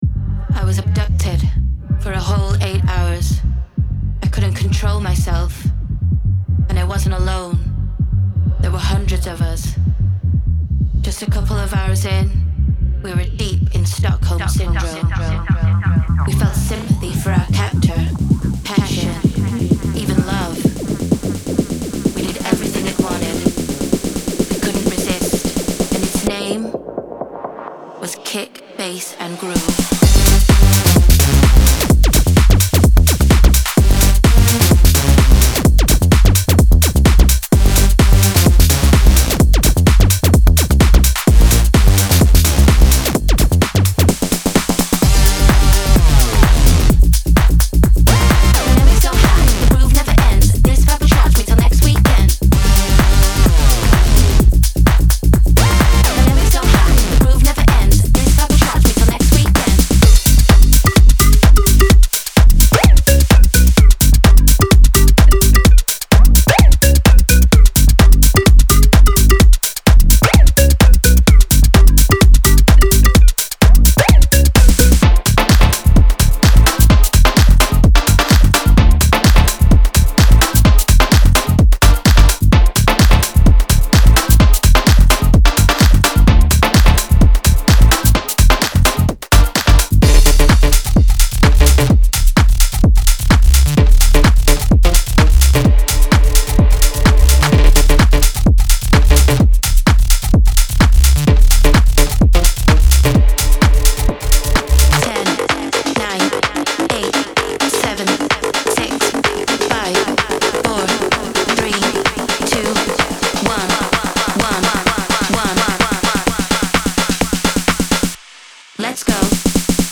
グルーヴィーでパワフル、そしてダンスフロアのために作られたサウンドです。
さらに特筆すべきは、このライブラリのすべてのサウンドがハードウェア機材を使用して処理されている点です。
デモサウンドはコチラ↓
Genre:Tech House
40 Bass Loops
36 Synth Loops
22 Vocal Loops